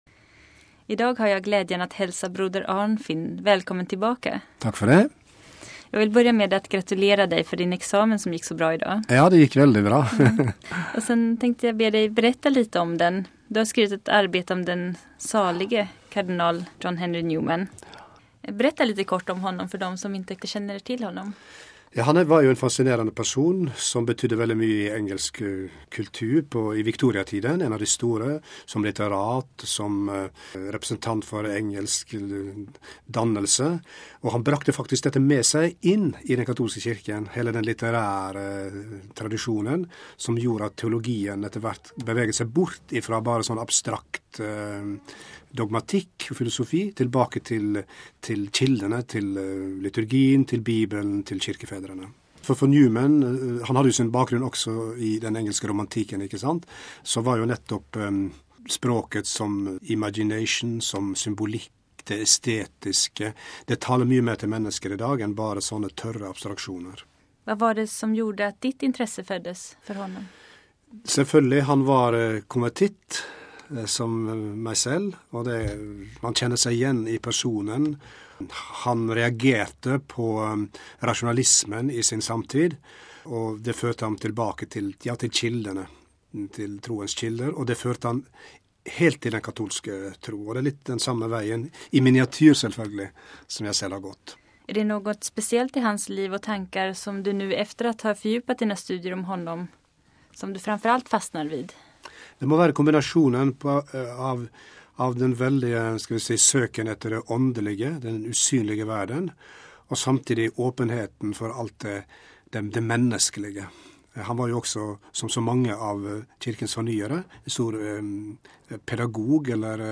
Två norska intervjuer.